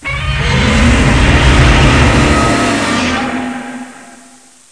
Hear the thunderous, earshattering roar of